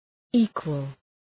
Shkrimi fonetik {‘i:kwəl}